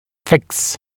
[fɪks][фикс]фиксировать, закреплять